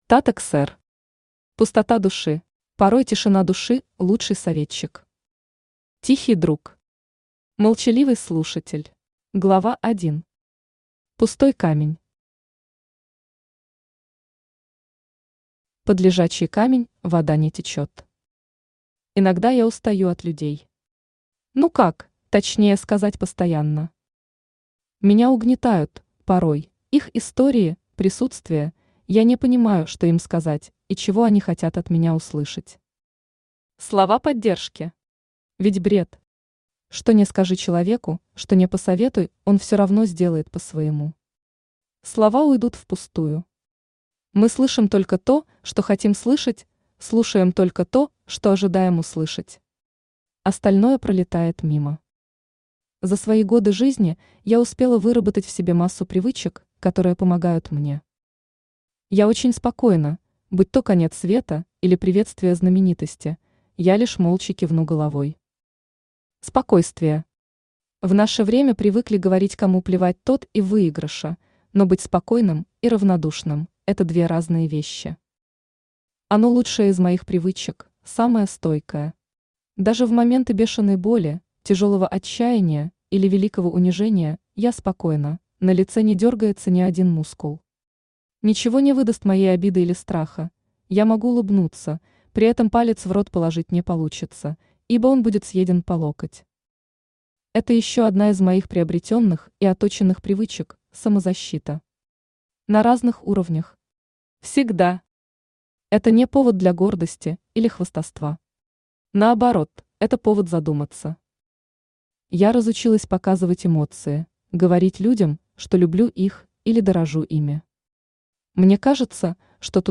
Аудиокнига Пустота души | Библиотека аудиокниг
Aудиокнига Пустота души Автор Tata Ksr Читает аудиокнигу Авточтец ЛитРес.